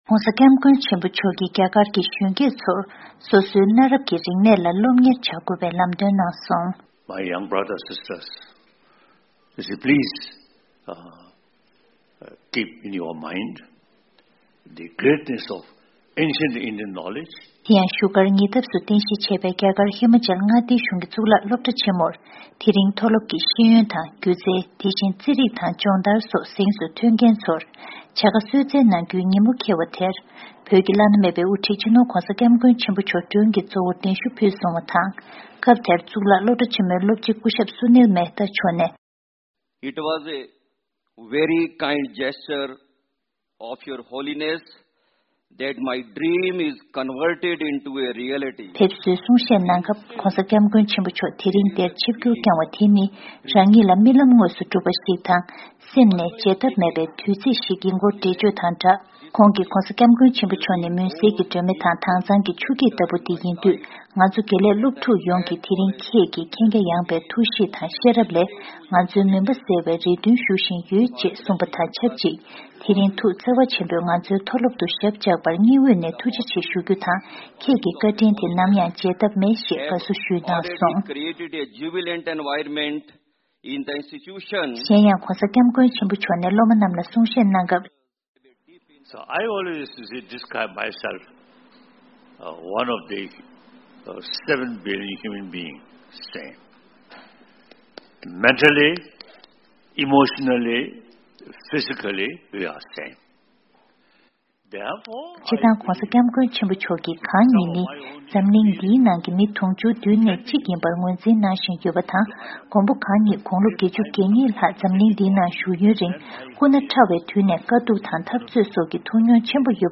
བཞུགས་སྒར་ནས་བཏང་བའི་གནས་ཚུལ་